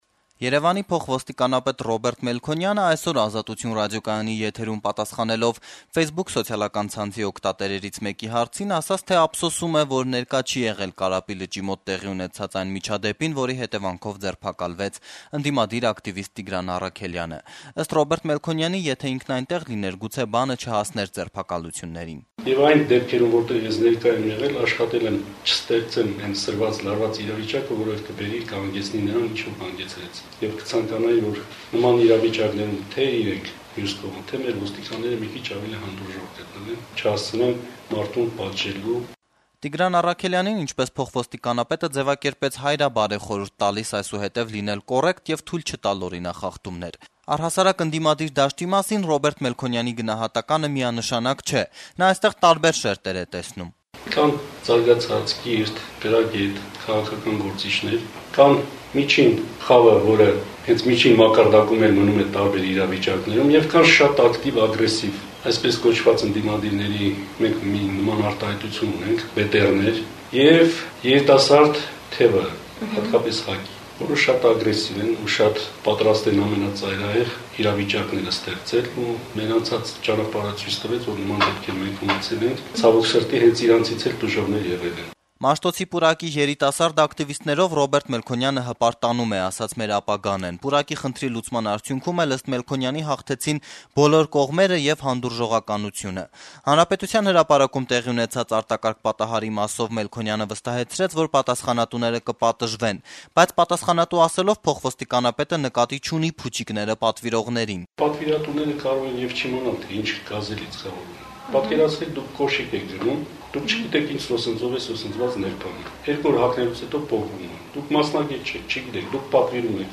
Երեւանի փոխոստիկանապետ Ռոբերտ Մելքոնյանը «Ազատություն» ռադիոկայանի ֆեյսբուքյան ասուլիսի ընթացքում պատասխանեց օգտատերերի հարցերին: